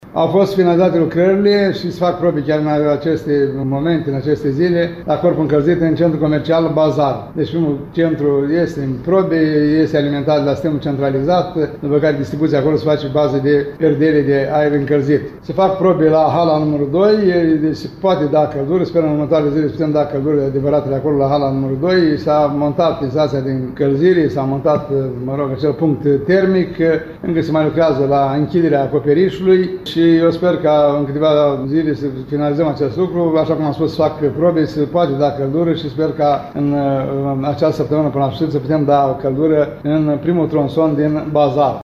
Primarul ION LUNGU a declarat astăzi că instalațiile montate vor asigura perdele de aer cald, contribuind la creșterea gradului de civilizație pentru vânzători și cumpărători.